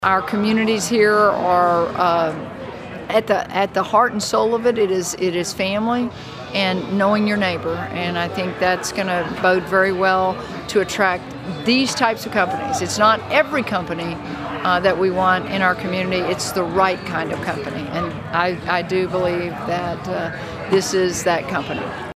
State Senator Lois Kolkhorst congratulated the city and the county for their hard work in securing the project, saying it will be a big contributor.